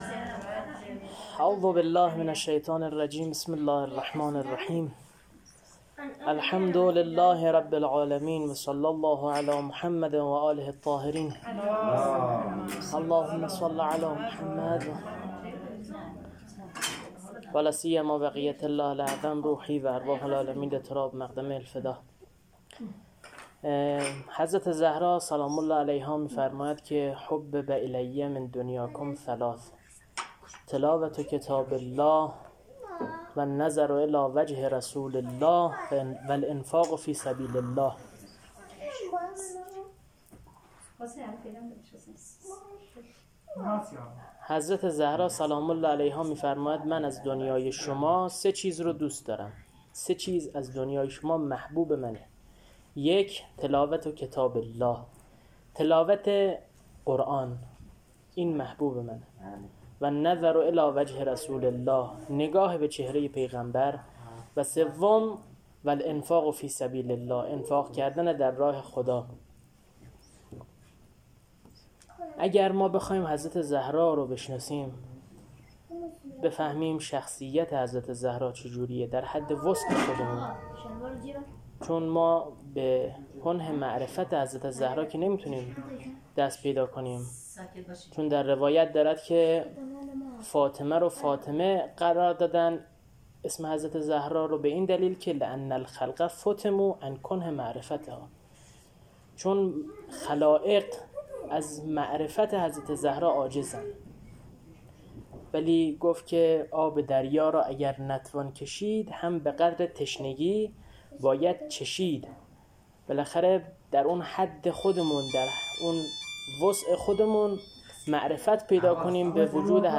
سخنرانی روز شهادت حضرت زهرا سلام الله علیها .mp3
سخنرانی-روز-شهادت-حضرت-زهرا-سلام-الله-علیها.mp3